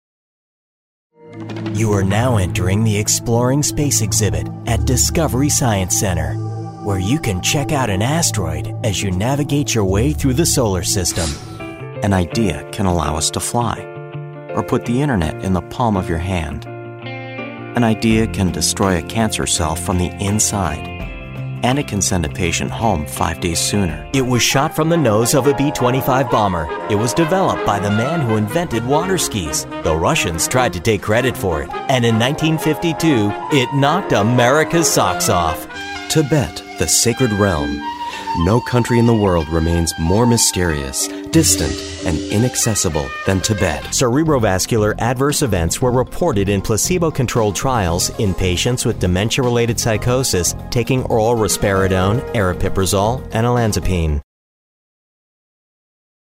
Male Narration Voice Talent
Friendly, Guy Next Door, Authoritative: A smooth rich tone that is not too heavy.
Narrations
A voice that conveys trust, meaning, and believability for a wide-range of uses.